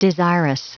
Prononciation du mot desirous en anglais (fichier audio)
Prononciation du mot : desirous